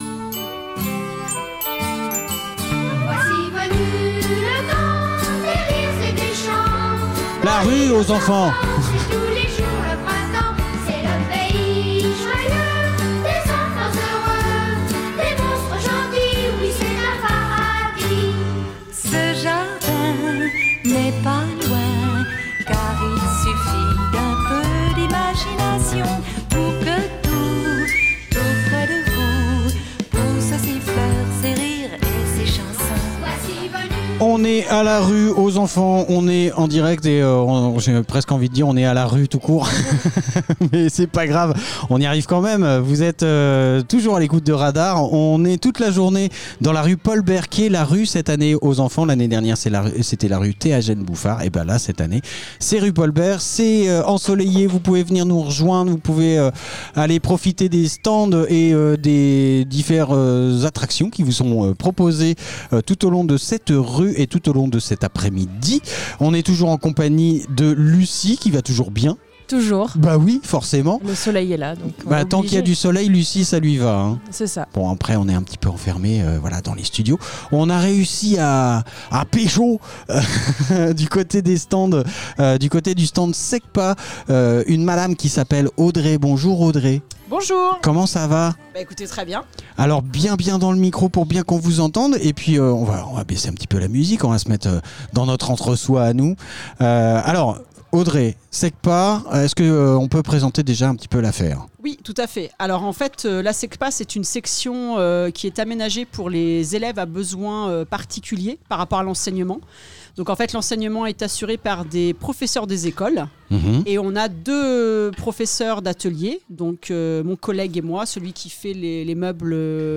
La rue aux enfants Interview fécamp associations association rue aux enfants